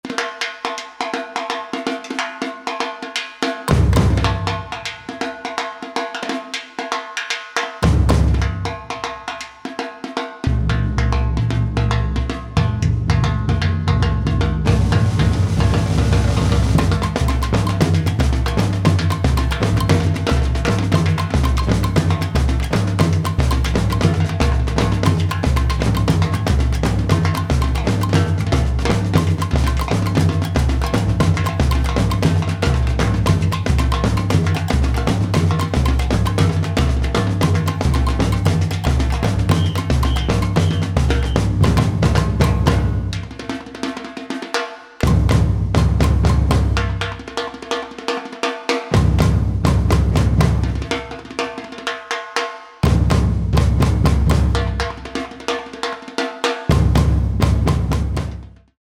Recorded 2005 at Whitby Studio, Ellesmere Port.